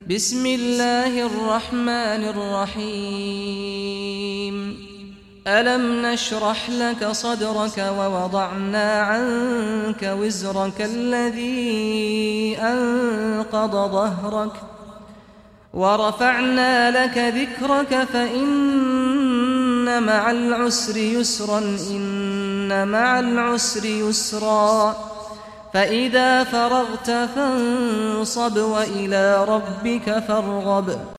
Surah Ash-Sharh Recitation by Sheikh Saad Ghamdi
Surah Ash-Sharh, listen or play online mp3 tilawat / recitation in Arabic in the beautiful voice of Imam Sheikh Saad al Ghamdi.